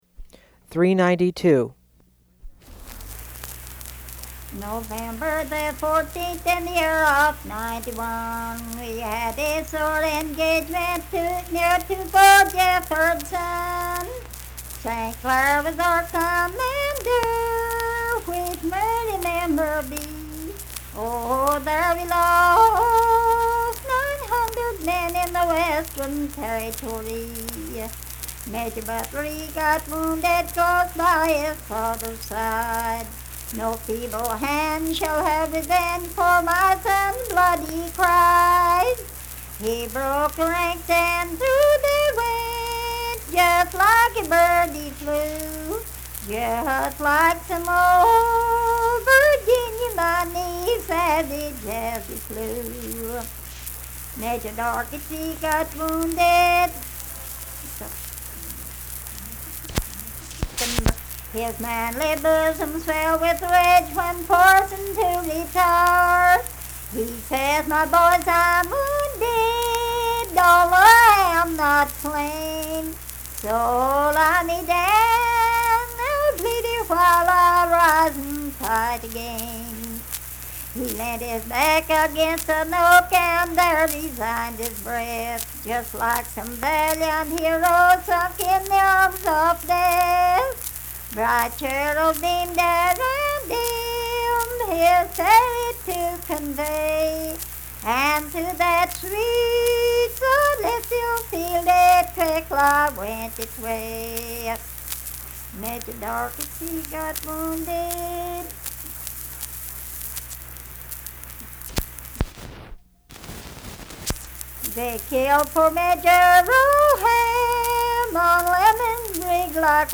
Unaccompanied vocal music
Verse-refrain 6d(4).
Voice (sung)